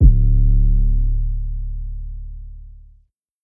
Karma 808.wav